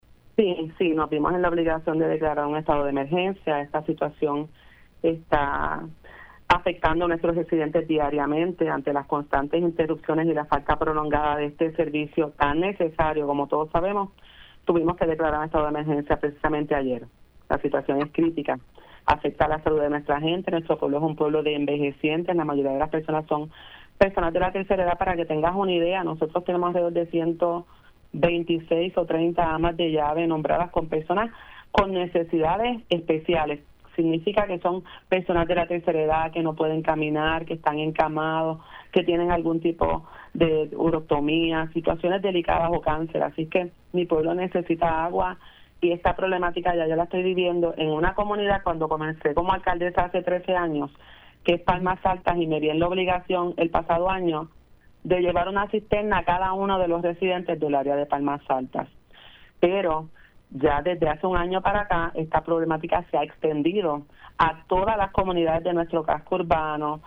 La alcaldesa de Barceloneta, Wanda Soler indicó en Pega’os en la Mañana que tuvo que declarar un estado de emergencia en su municipio debido a la emergente falta de agua potable a través de todo el pueblo.